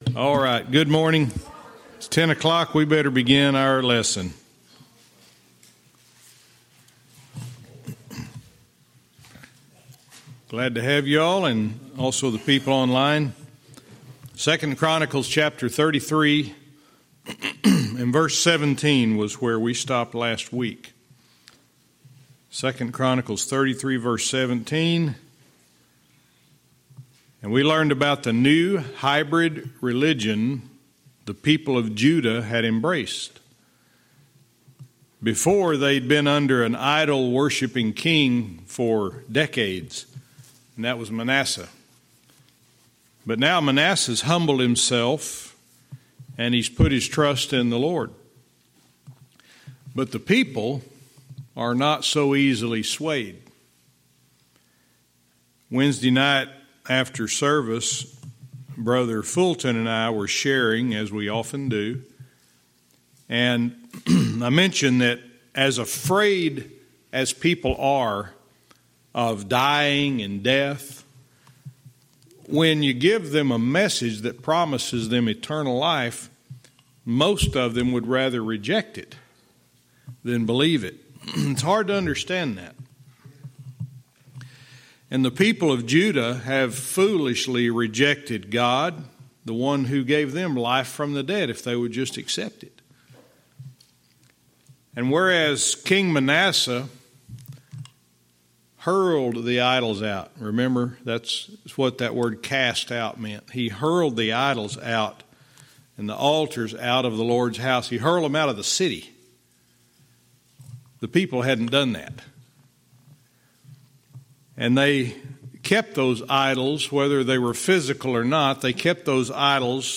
Verse by verse teaching - 2 Kings 21:18(cont)-21 & 2 Chronicles 33:17-20